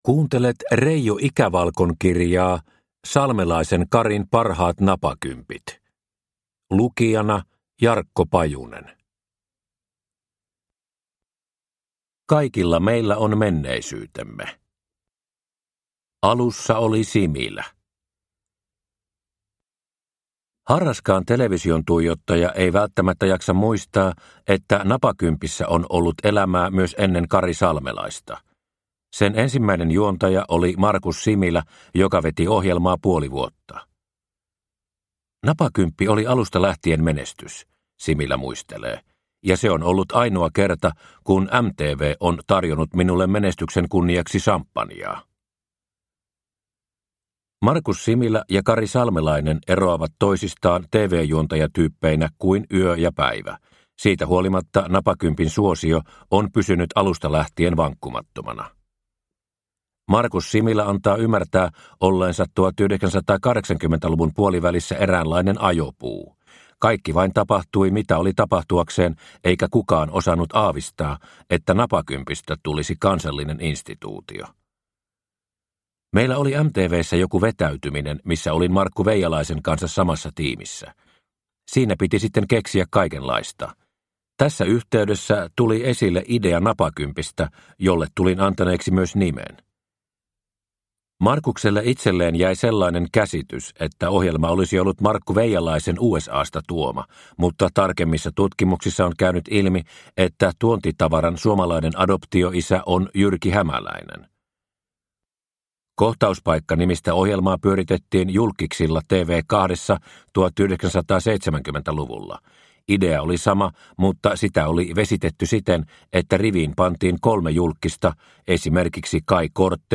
Salmelaisen Karin napakympit – Ljudbok – Laddas ner